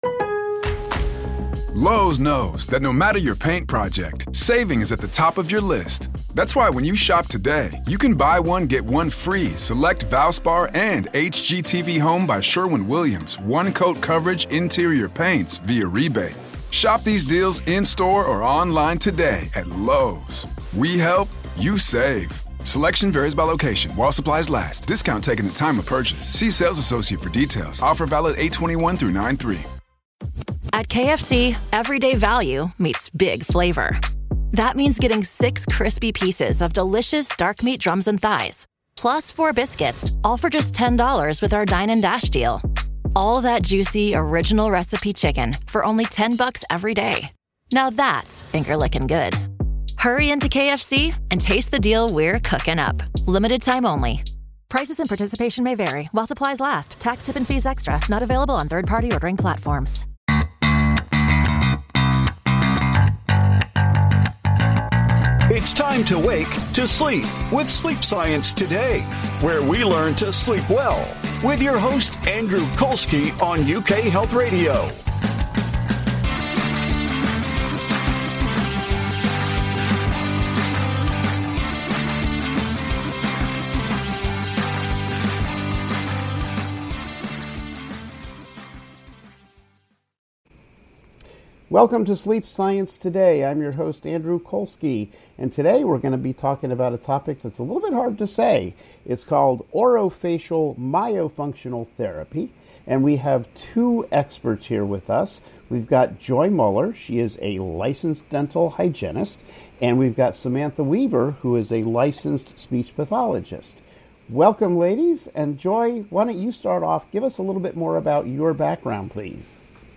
Doctors have learned that restful sleep is critical for your physical and mental health. You will hear from renowned sleep experts as they share the latest information about how to sleep better with science.